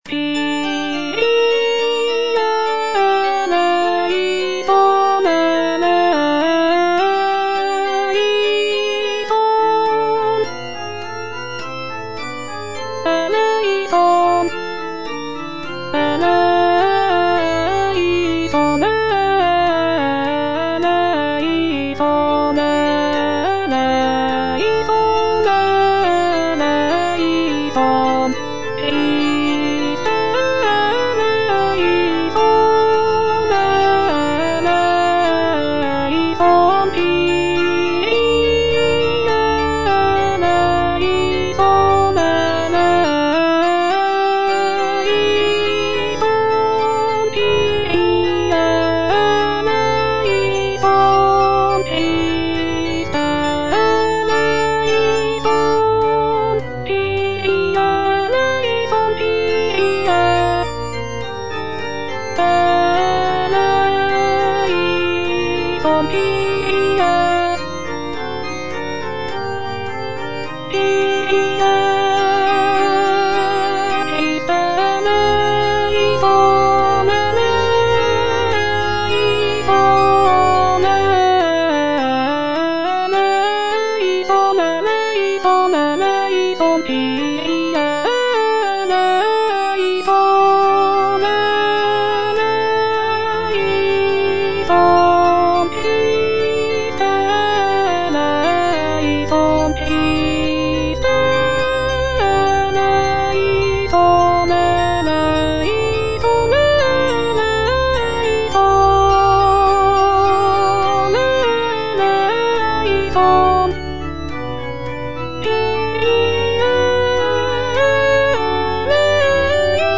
Kyrie - Alto (Voice with metronome) Ads stop